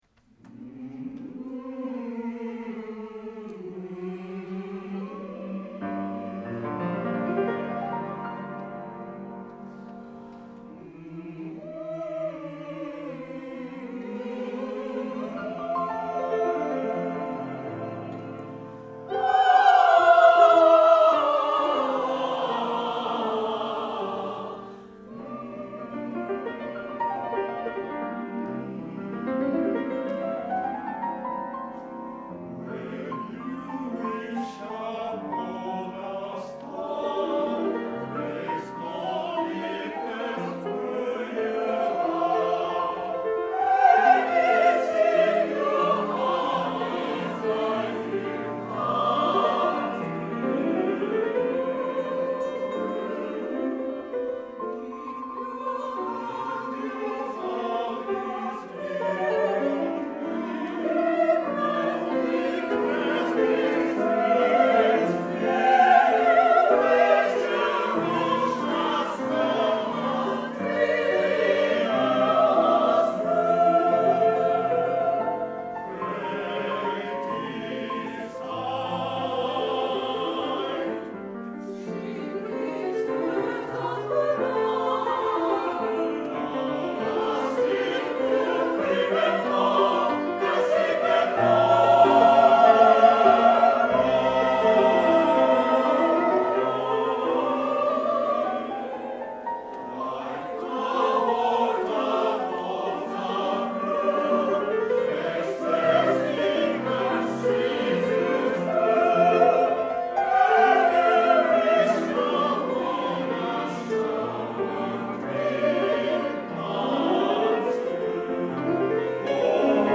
第４４回　Ｆ合唱祭　各団体の演奏